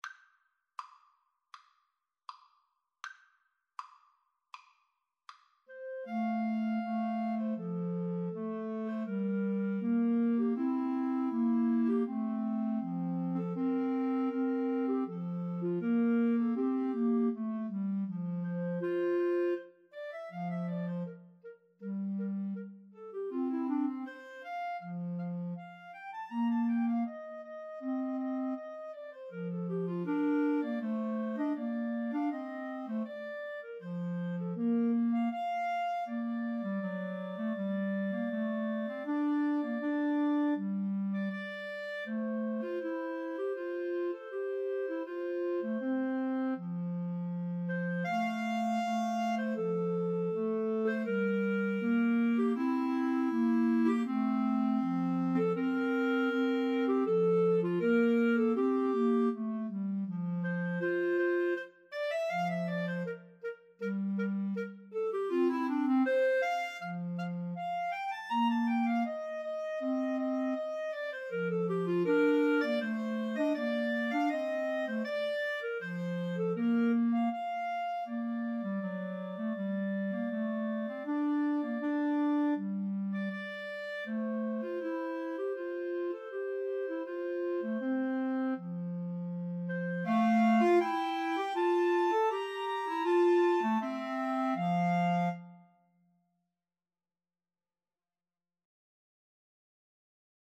= 80 Andante
Classical (View more Classical Clarinet Trio Music)